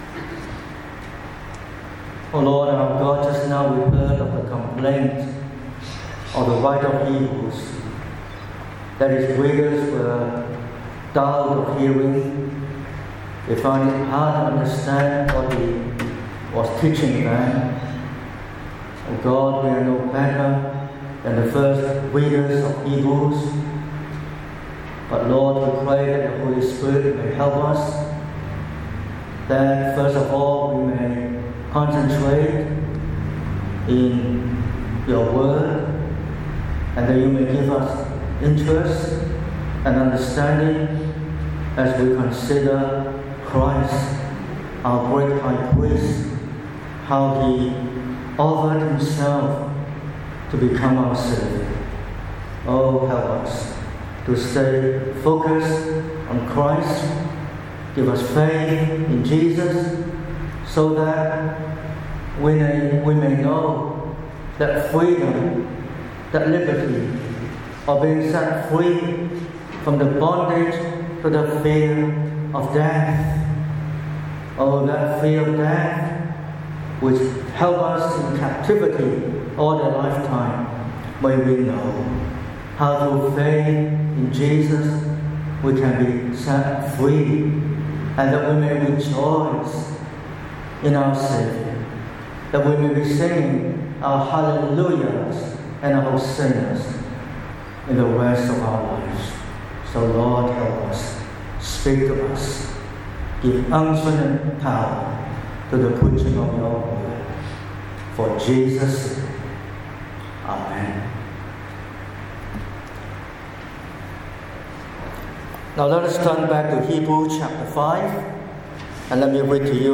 03/04/2026 – Good Friday Service: Hebrews 5:5–10
Sermon Outline